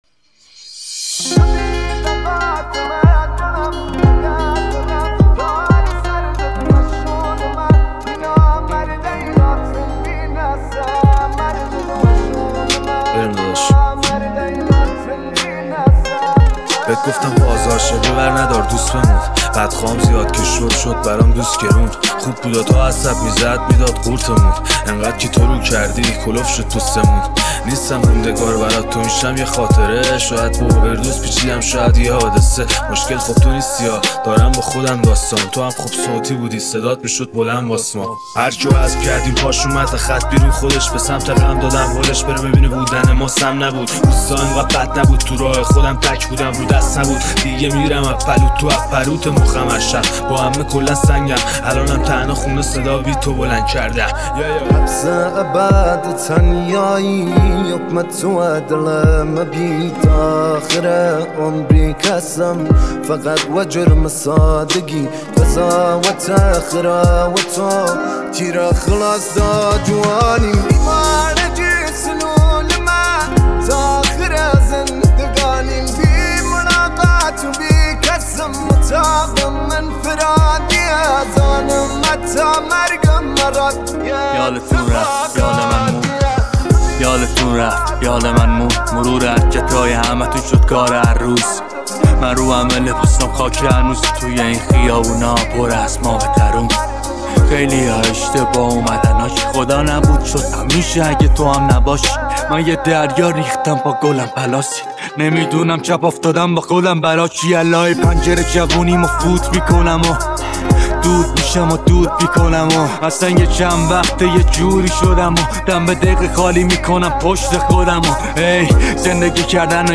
دانلود رپ جدید